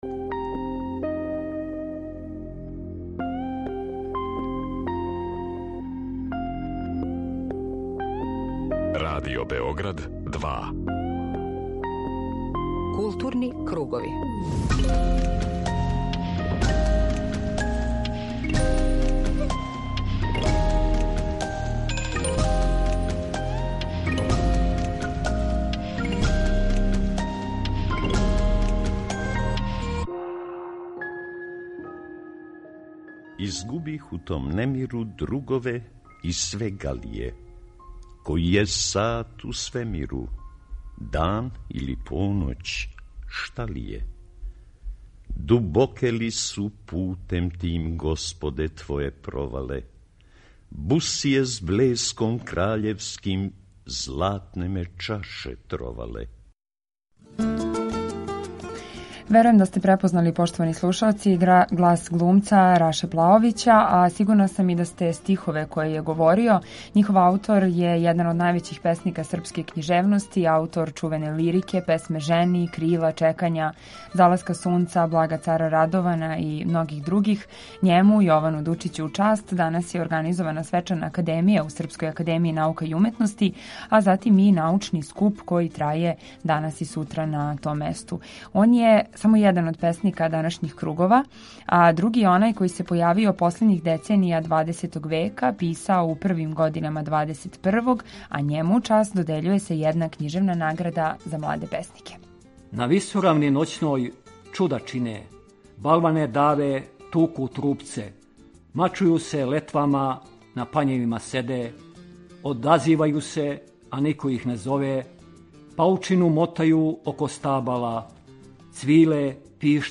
Група аутора Централна културно-уметничка емисија Радио Београда 2.
Говориће академик Љубомир Симовић, а Дучићеве стихове у Симовићевом избору говориће глумци Воја Брајовић и Небојша Кундачина.